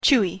/ˈkuɪ(米国英語), ˈku:ɪ(英国英語)/